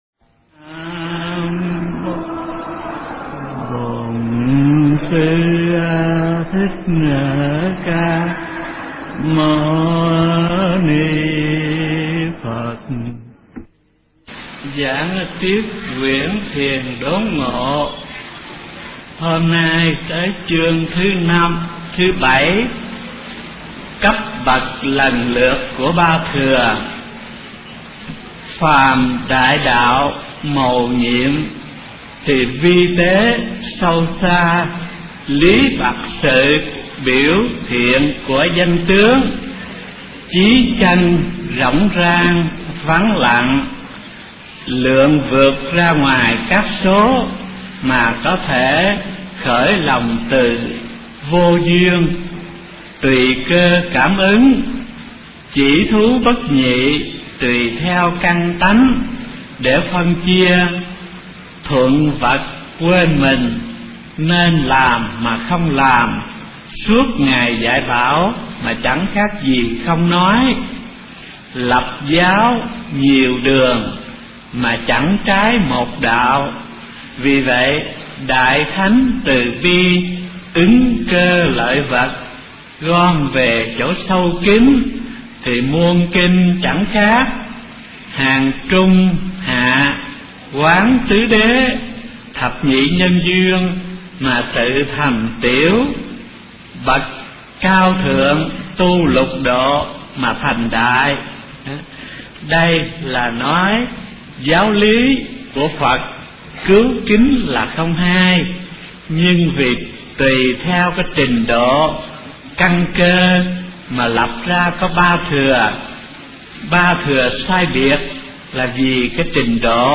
Kinh Giảng Thiền Tông Vĩnh Gia Tập - Thích Thanh Từ